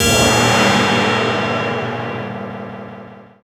CHINA FX2.wav